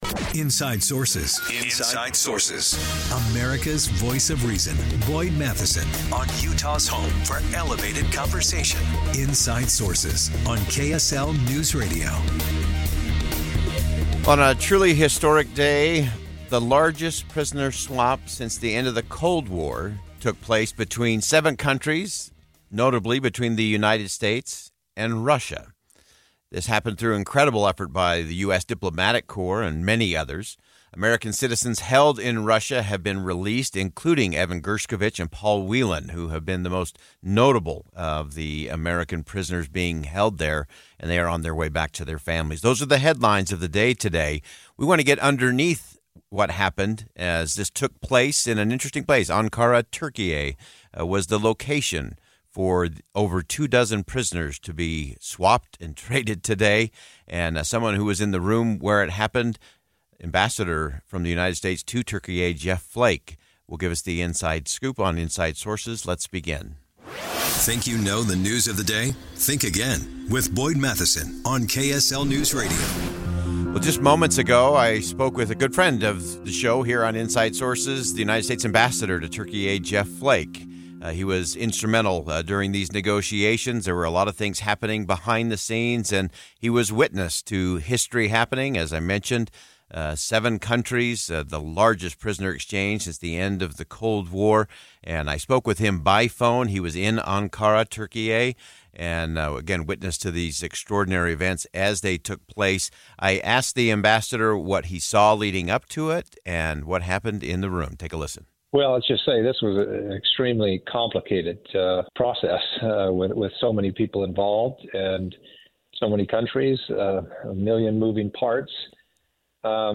U.S. Ambassador to Turkey Jeff Flake joins Inside Sources to describe what happened at the prisoner swap between the U.S. and Russia.